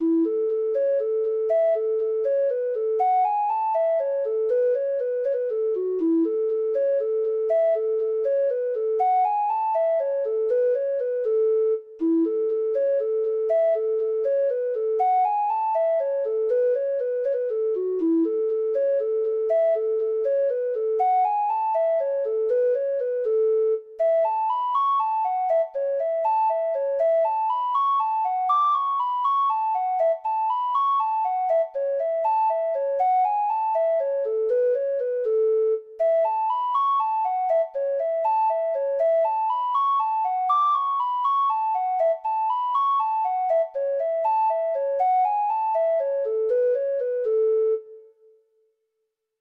Traditional Music of unknown author.
Treble Clef Instrument Sheet Music
Irish